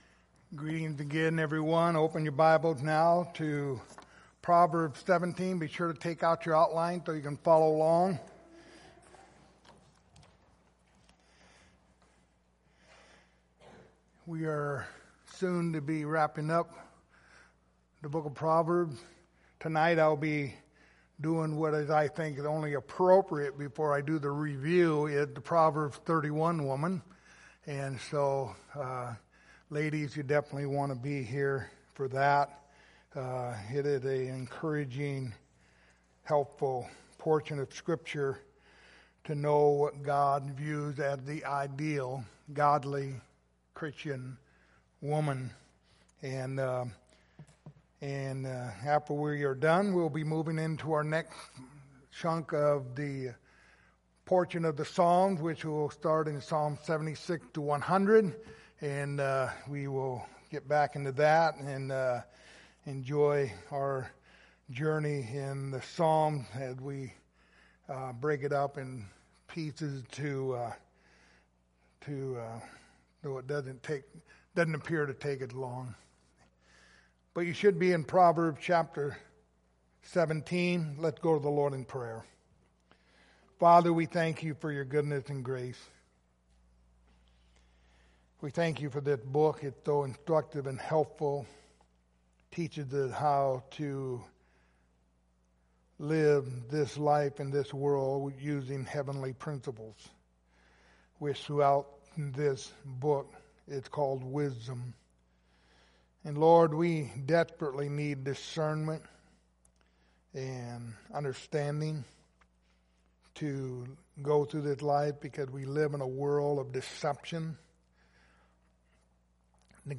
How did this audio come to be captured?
Series: The Book of Proverbs Passage: Proverbs 2:2 Service Type: Sunday Morning